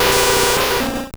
Cri de Nostenfer dans Pokémon Or et Argent.